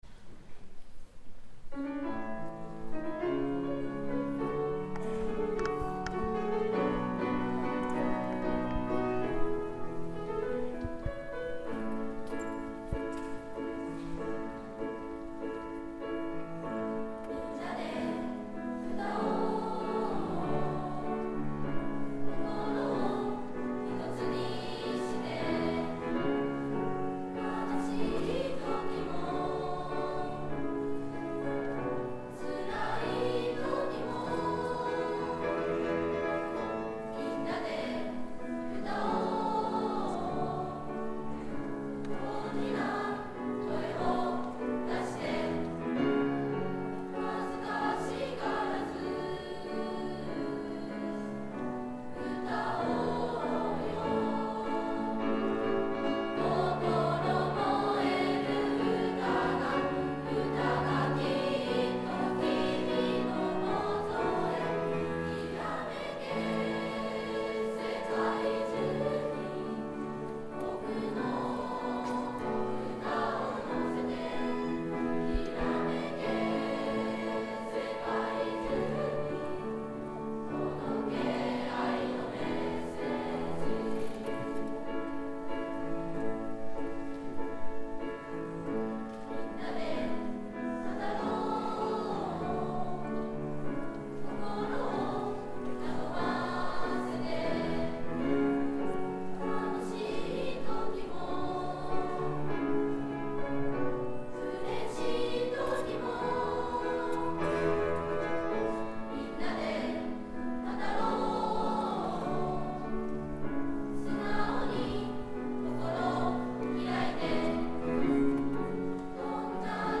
１Ｃ マイバラード.mp3←クリックすると合唱が聴けます